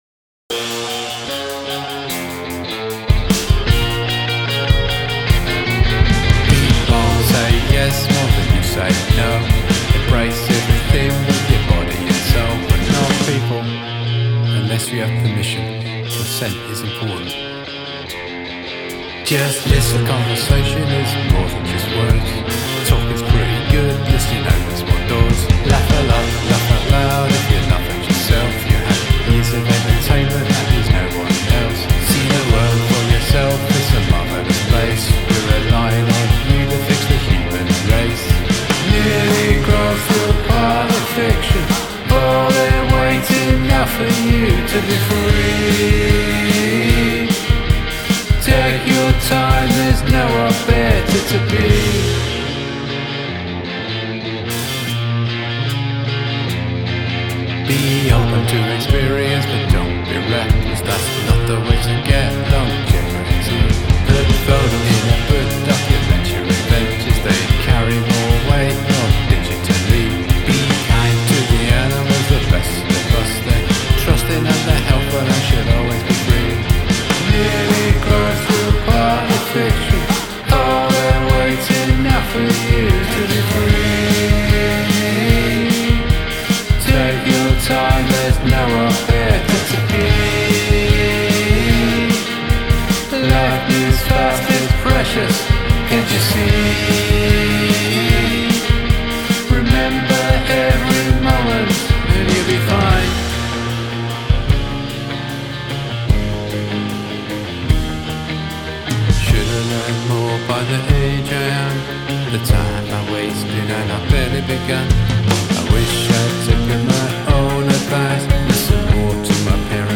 List Song
I like Brits who sing with British accents.